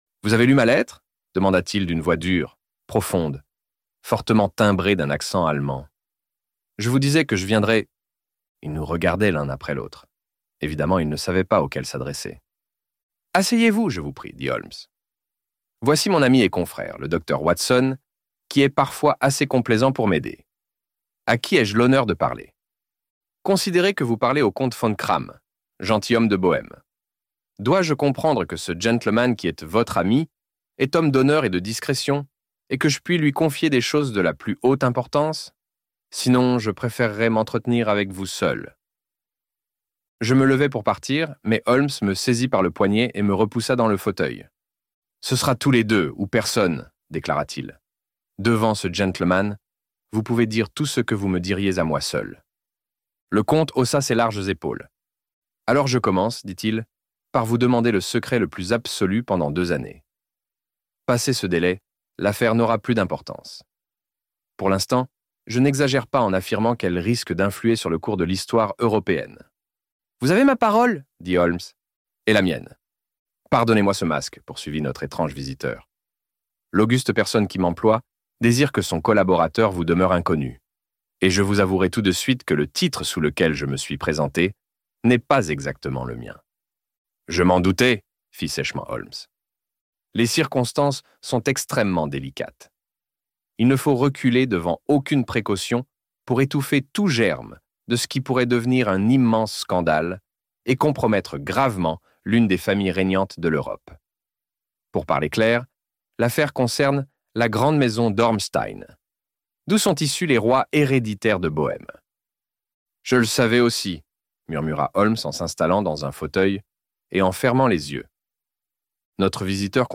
Les Aventures de Sherlock Holmes - Livre Audio